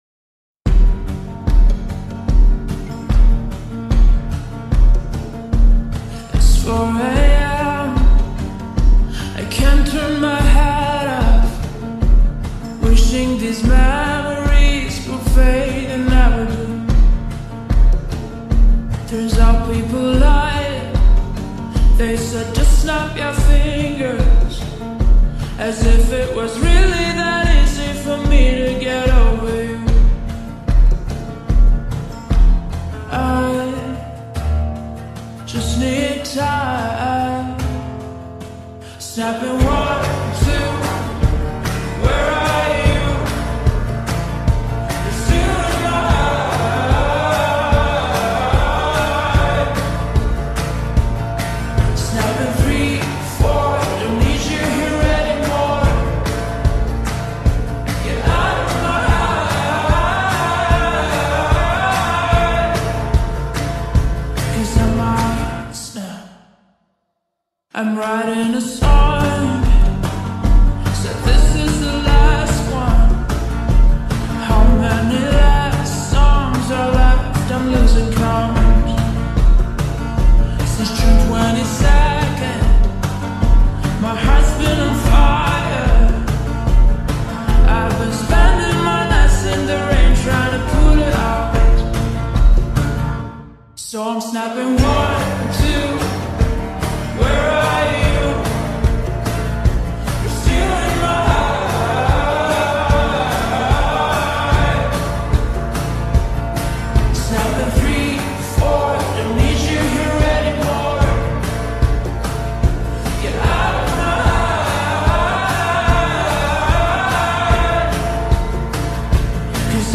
نسخه Slowed و کند شده
غمگین